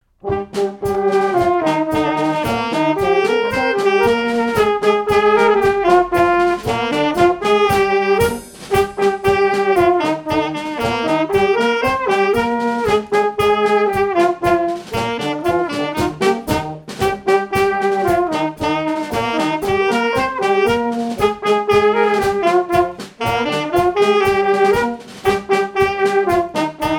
Champagné-les-Marais
danse : polka
Pièce musicale inédite